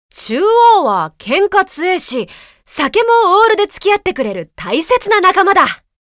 山海战记_苏轼_日文台词_14.mp3